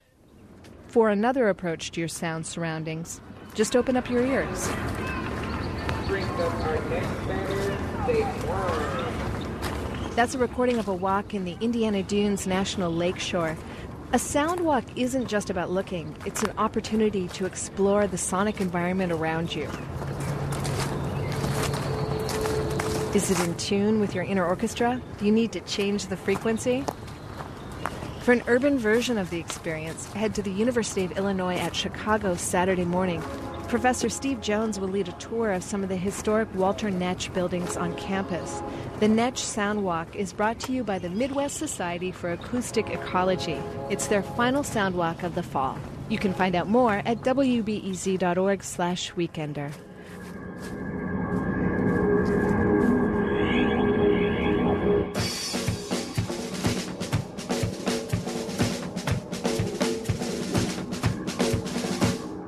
WBEZ_Weekender_Soundwalk.mp3